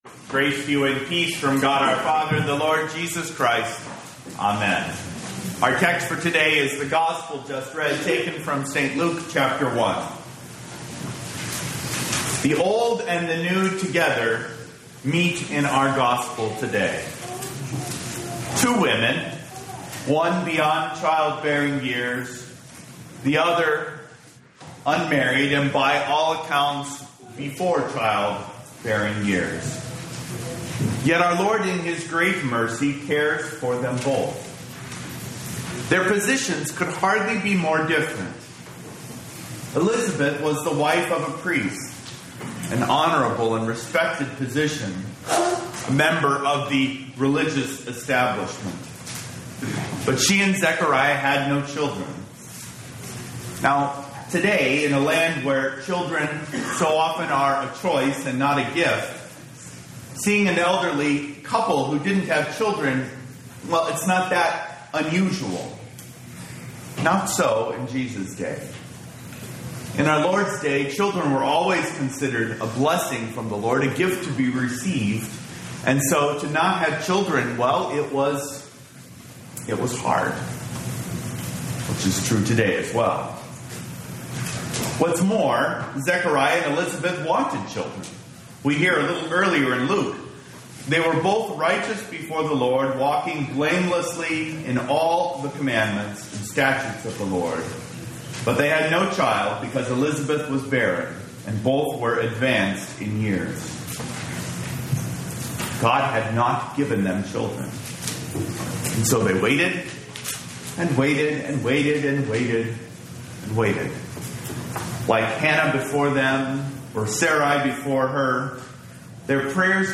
Holy Cross Lutheran Church Rocklin, California